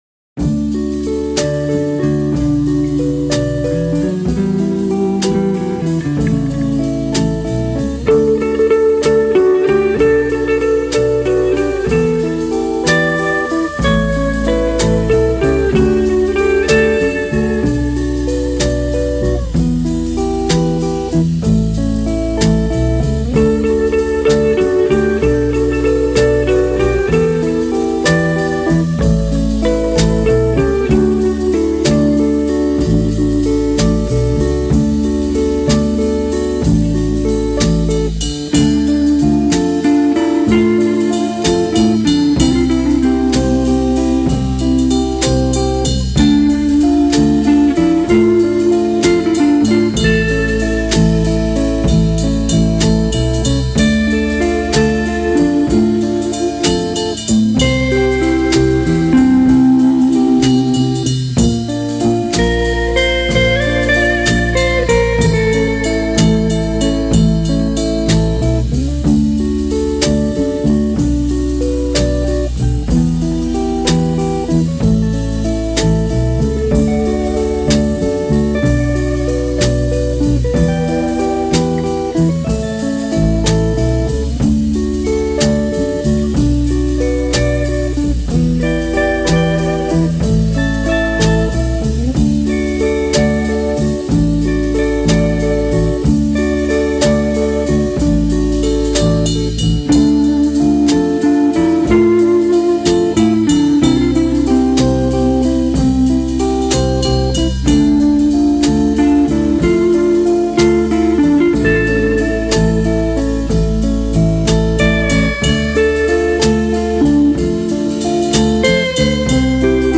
Tema grabado por la banda inglesa instrumental